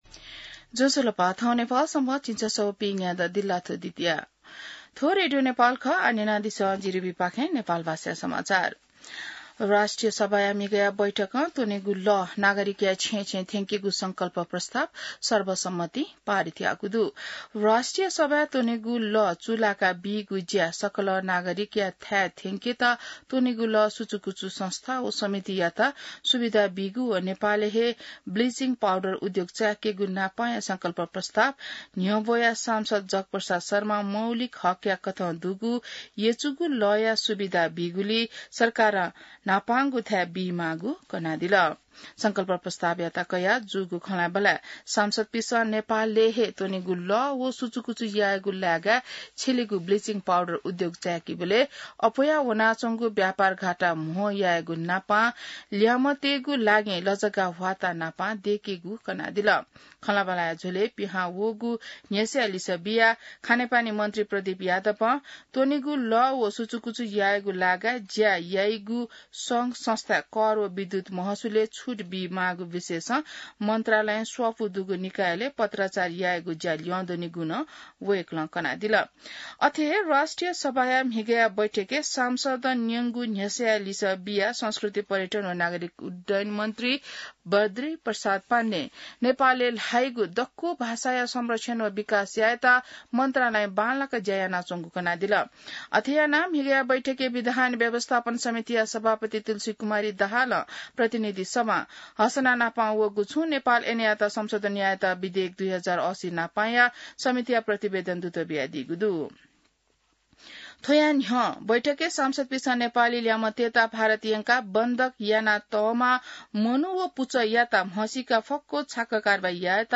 An online outlet of Nepal's national radio broadcaster
नेपाल भाषामा समाचार : १३ असार , २०८२